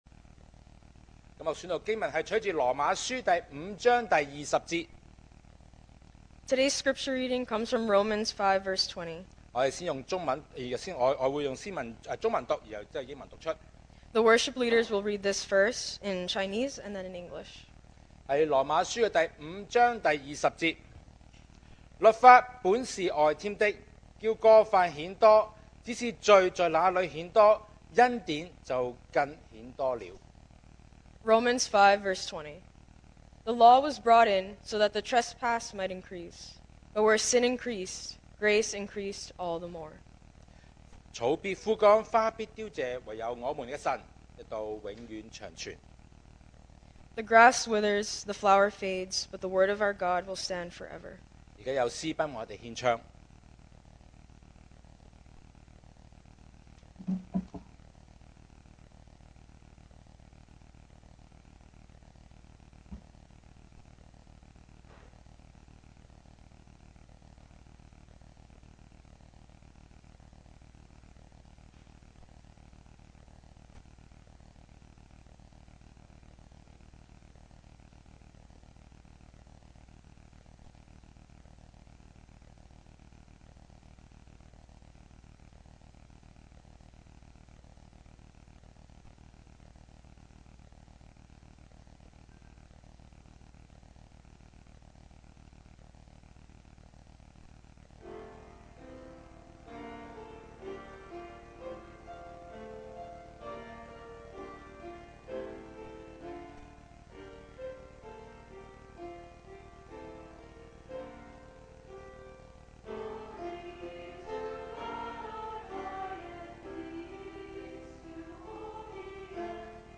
sermon audios
Service Type: Sunday Morning